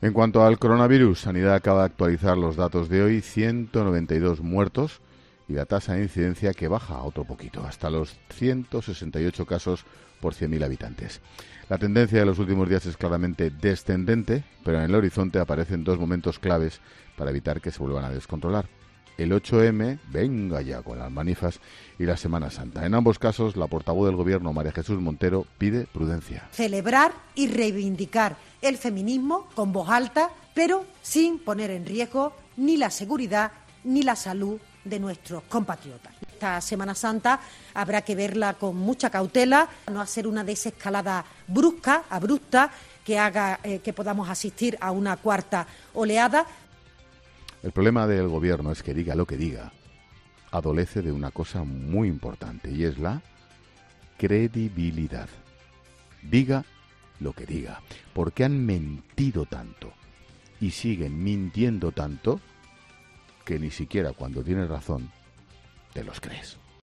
El presentador de 'La Linterna' no acaba de confiar en las últimas palabras de la portavoz del Gobierno